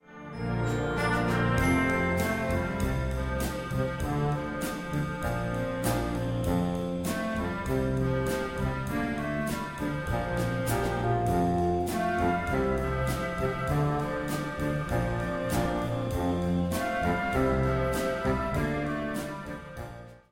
Besetzung: Blasorchester
ballad
This feel-good melody